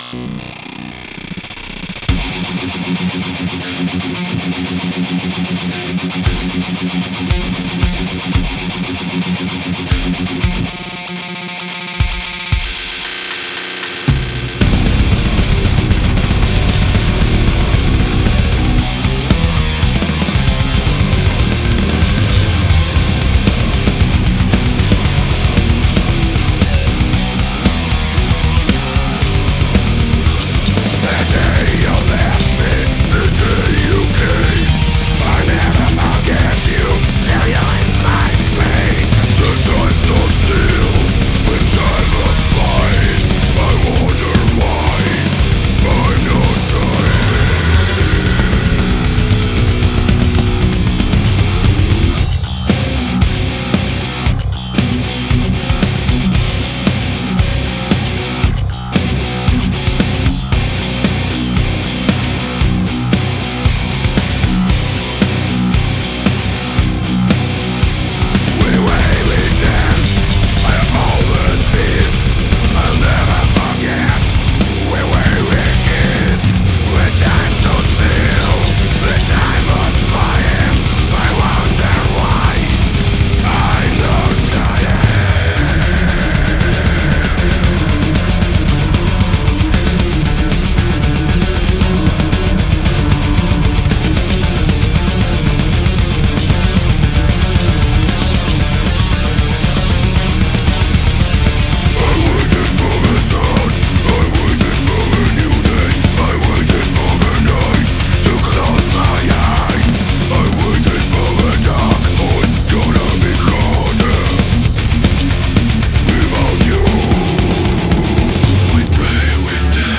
экстремальный металл )